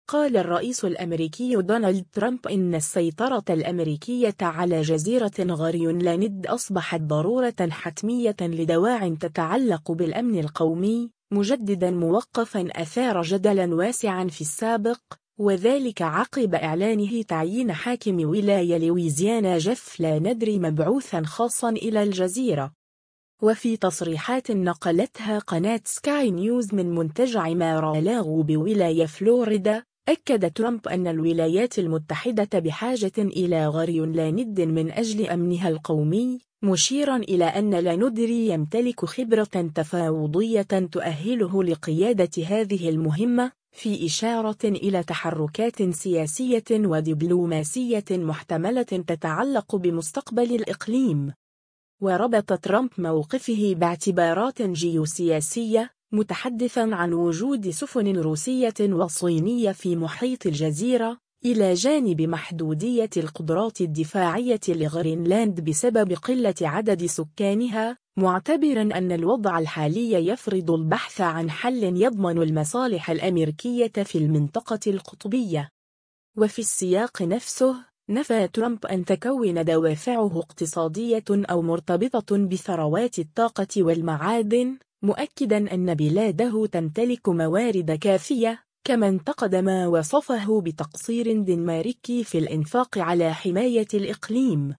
و في تصريحات نقلتها قناة “سكاي نيوز” من منتجع مارالاغو بولاية فلوريدا، أكد ترامب أن الولايات المتحدة بحاجة إلى غرينلاند من أجل أمنها القومي، مشيرًا إلى أن لاندري يمتلك خبرة تفاوضية تؤهله لقيادة هذه المهمة، في إشارة إلى تحركات سياسية و دبلوماسية محتملة تتعلق بمستقبل الإقليم.